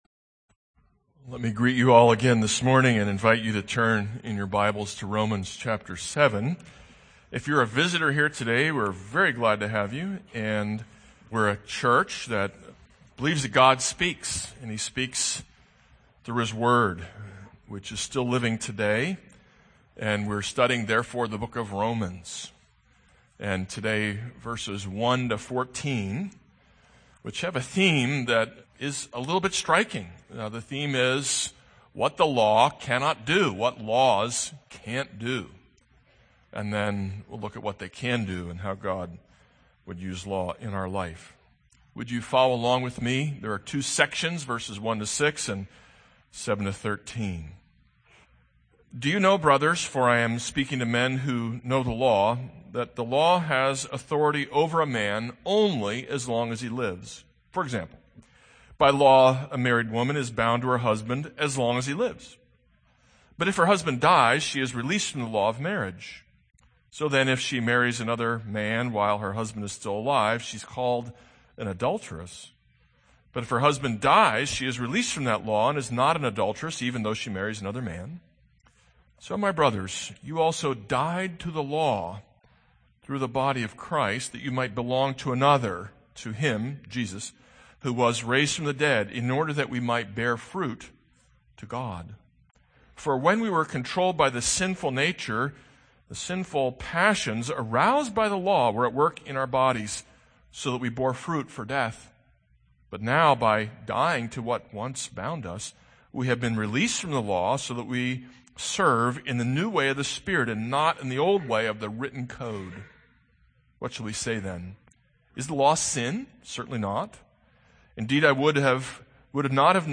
This is a sermon on Romans 7:1-14.